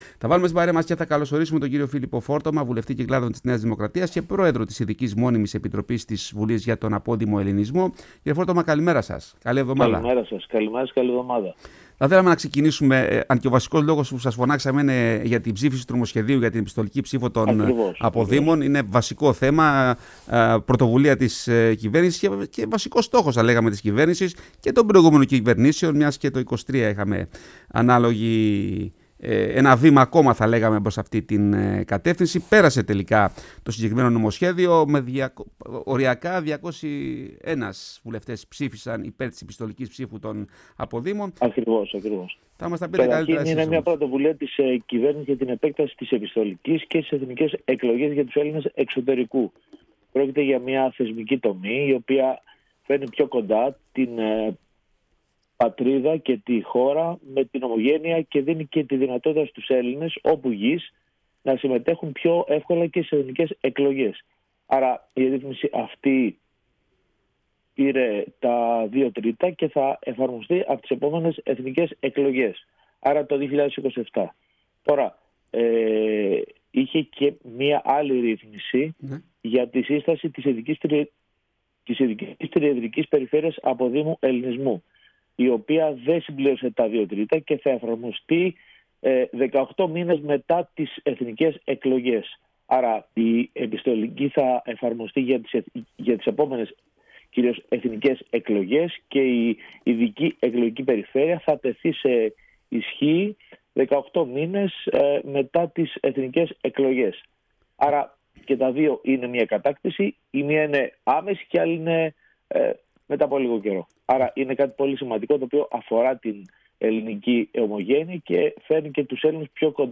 Η καθιέρωση της επιστολικής ψήφου διασφαλίζει την ισότιμη και απρόσκοπτη συμμετοχή όλων των Ελλήνων του εξωτερικού στις εθνικές εκλογές“, τόνισε μιλώντας σήμερα, Δευτέρα 9 Μαρτίου, στη “Φωνή της Ελλάδας”, ο βουλευτής Κυκλάδων της Νέας Δημοκρατίας και Πρόεδρος της Ειδικής Μόνιμης Επιτροπής της Βουλής για τον Απόδημο Ελληνισμό κ. Φίλιππος Φόρτωμας.